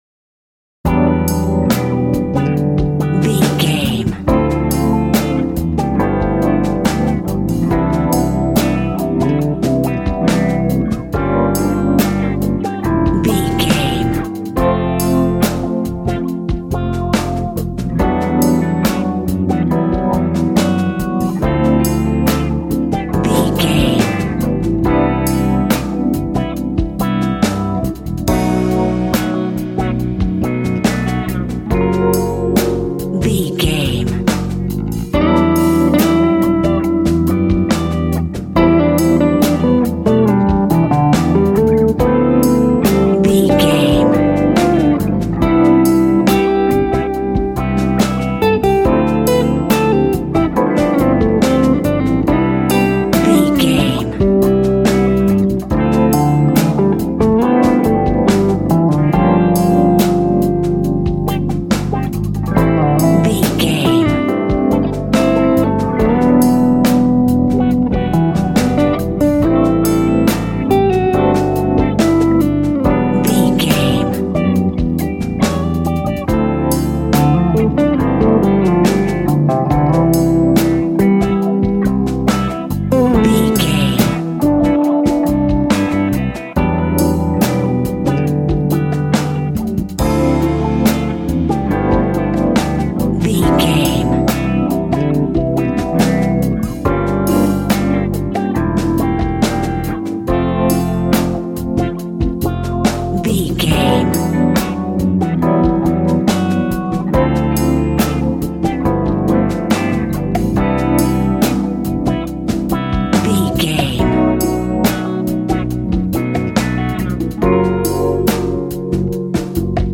Fast paced
In-crescendo
Uplifting
Ionian/Major
instrumentals